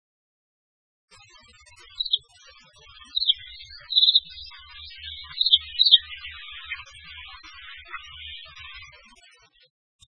〔カワラヒワ〕キリキリコロコロ，ビュィーン（さえずり）／低山の明るい林〜市街地
kawarahiwa.mp3